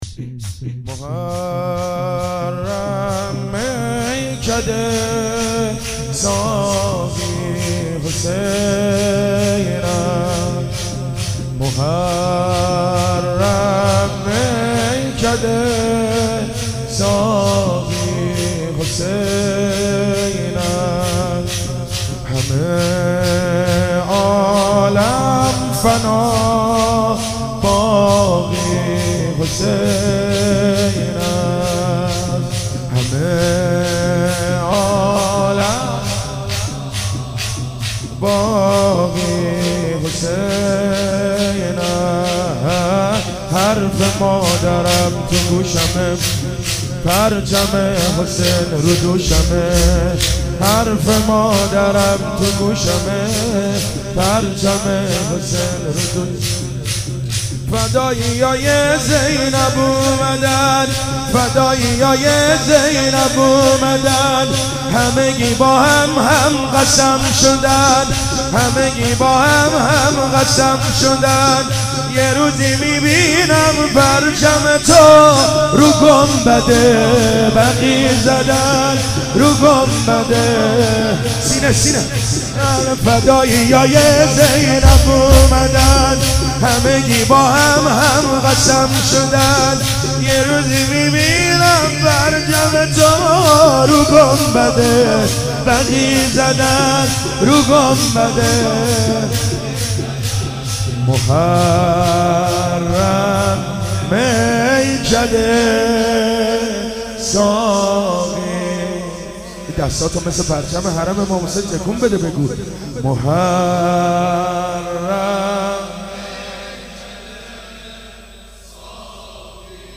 عصر روز دوم محرم95 /هیئت انصار هویزه
شور